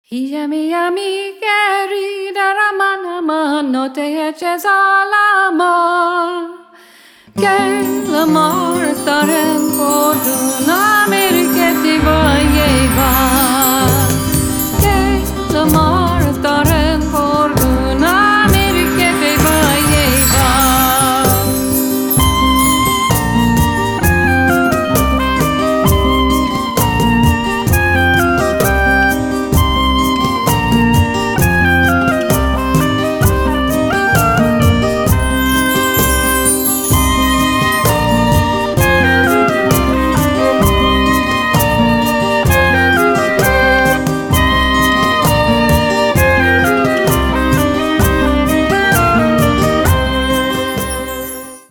Rich compelling melodies and tight driving rhythm
double bass
oboe
drums, percussion
violin, vocal Music More tracks are on our Bandcamp page.